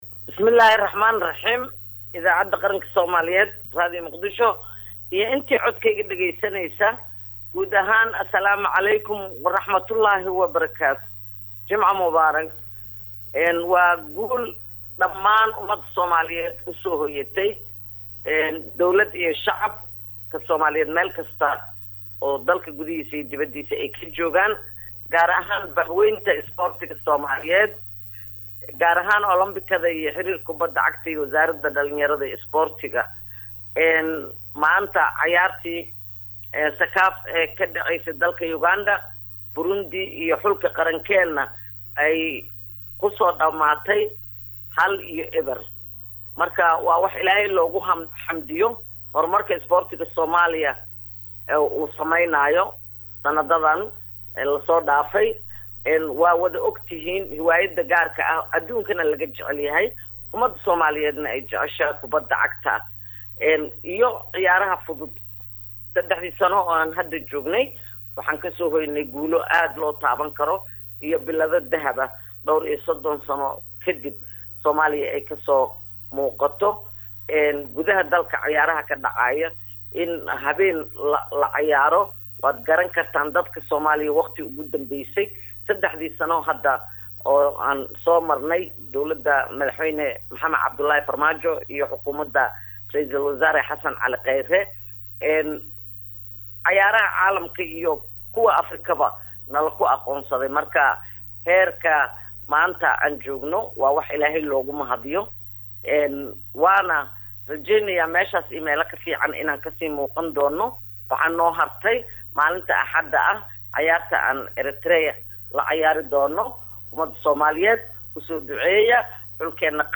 Wasiir Khadiija oo waryeysi gaar ah siisay Radio Muqdisho ayaa ugu horeyn Hambalyo u dirtay cayaartooyda Soomaaliyeed, waxaana ay guusha ay ka gaareen dhigooda Burundi ku tilmaamtay mid taariikhi ah oo ka turjumeysa horumarka Isboorti ee ay Soomaaliya ku talaabsatay.